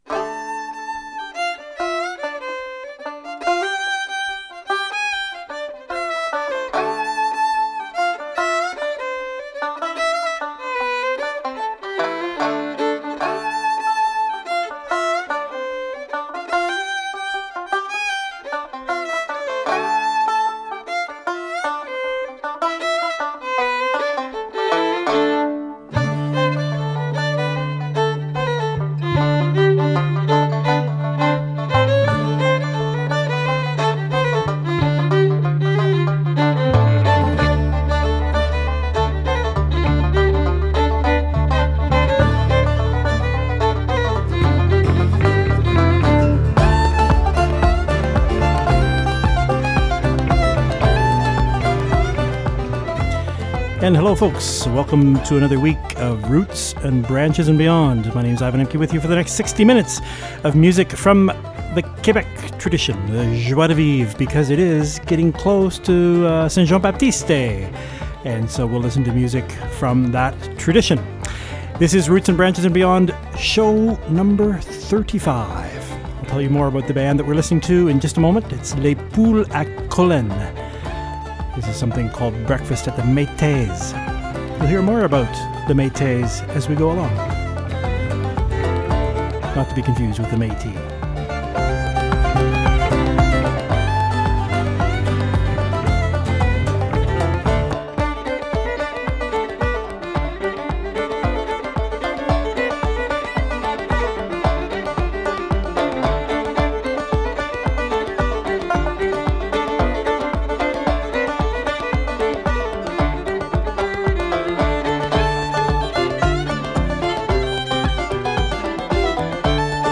La musique Quebecois